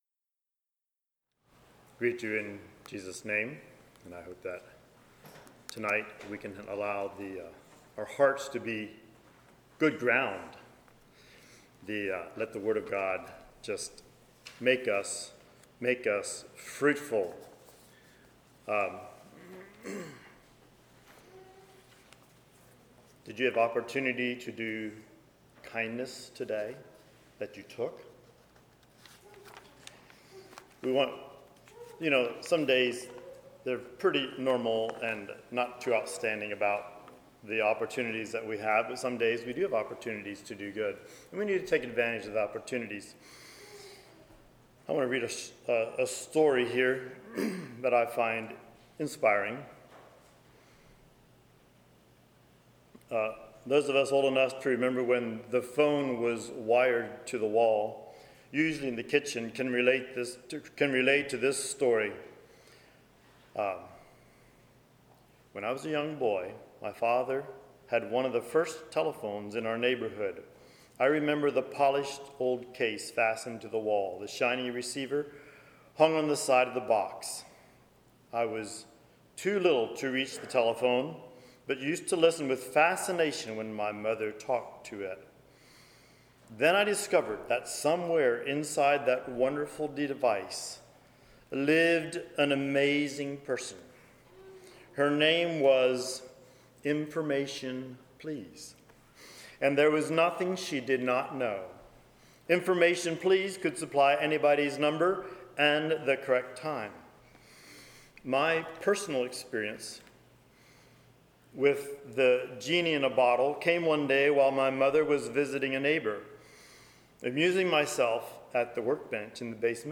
Sermons 08.08.23 Play Now Download to Device Who Am I?